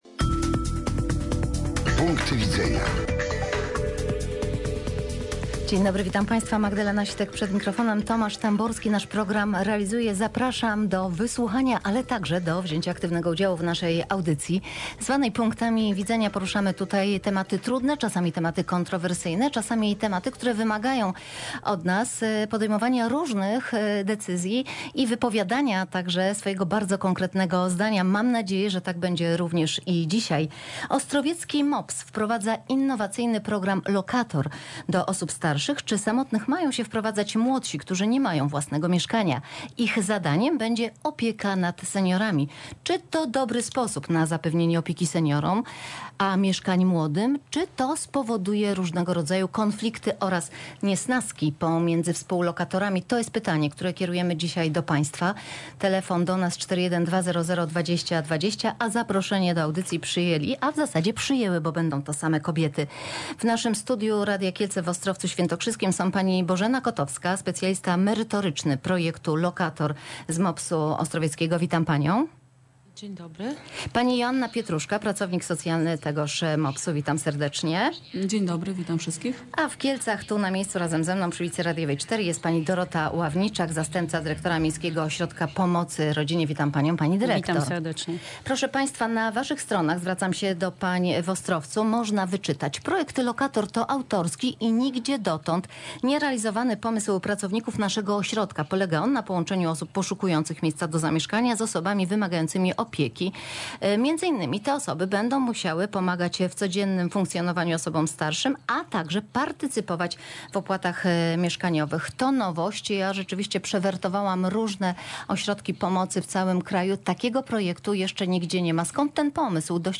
Ciekawa audycja odbyła się w Radio Kielce kilka lat temu. W trakcie rozmowy do radia dzwonili też słuchacze.